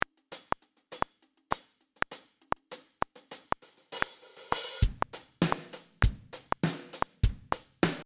In this case I’ve got a drum track about 40 seconds long.
Listening to these drums against the click it becomes apparent very quickly it’s not at 120bpm!
outoftime.mp3